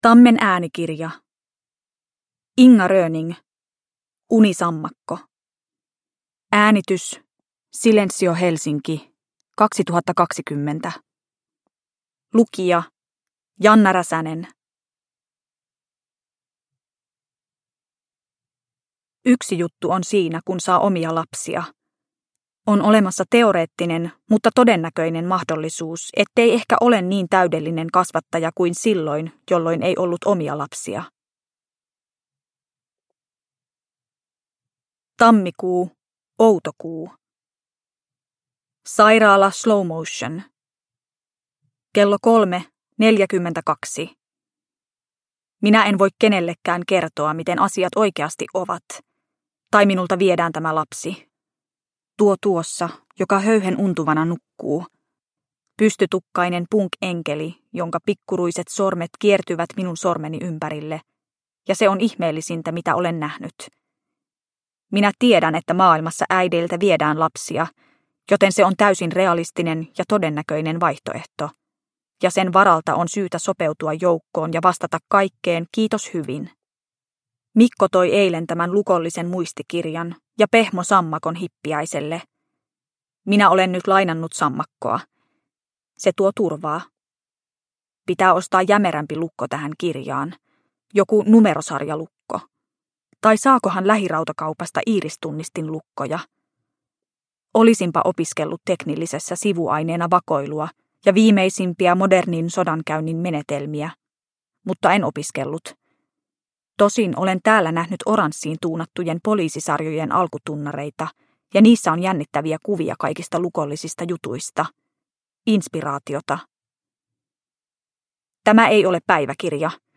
Unisammakko – Ljudbok – Laddas ner